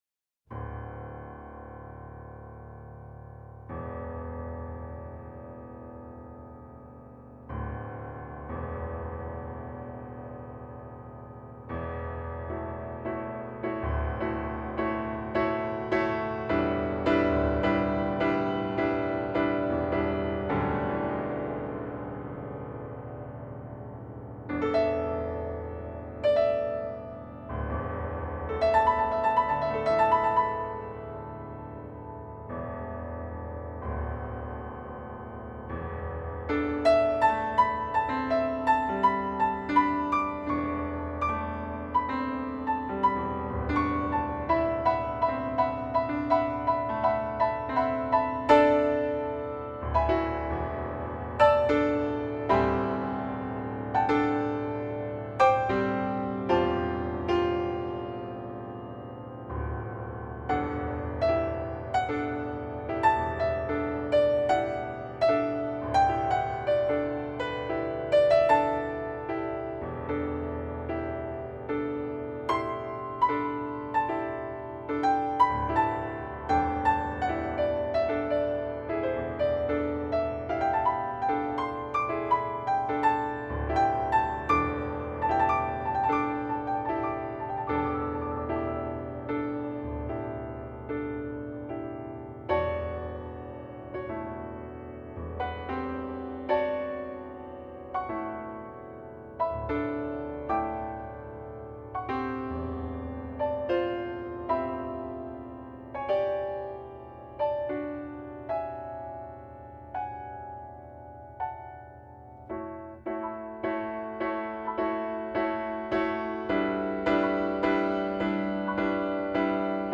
Yamaha Disklavier Duration: 11 min.
a memory piece for mechanical piano